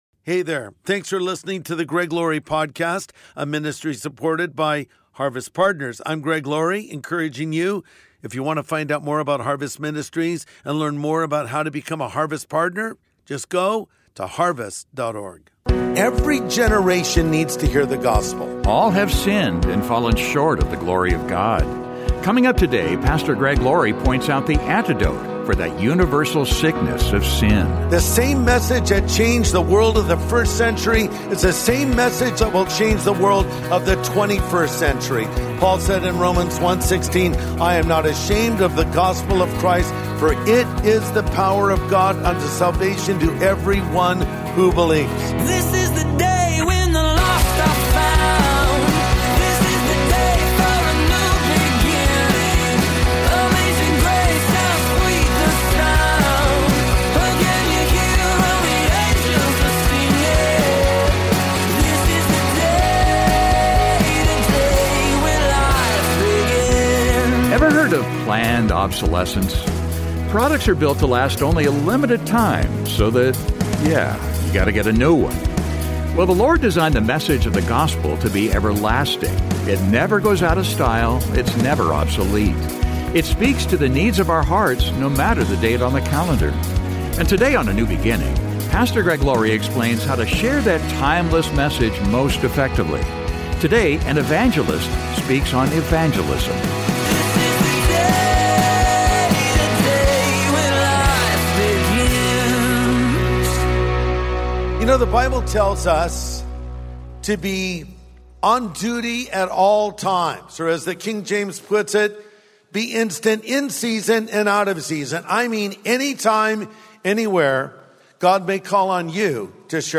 And today on A NEW BEGINNING, Pastor Greg Laurie explains how to share that timeless message most effectively. Today, an evangelist speaks on evangelism.